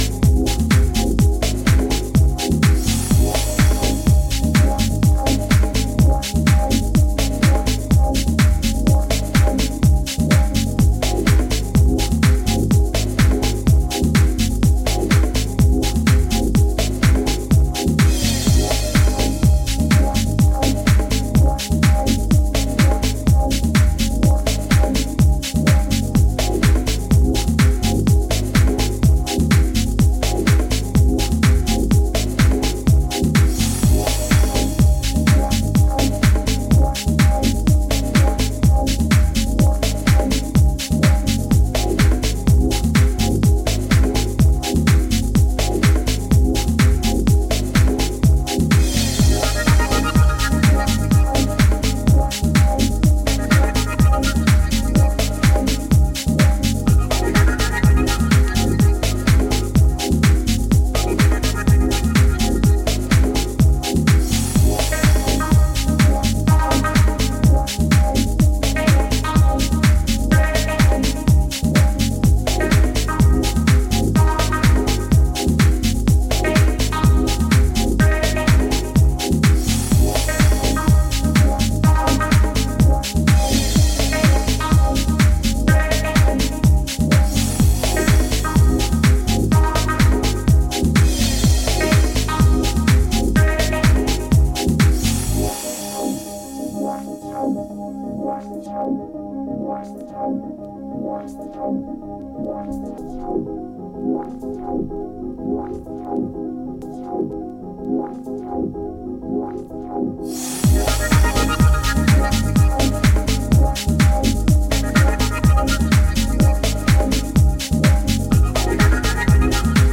Instrumental Mix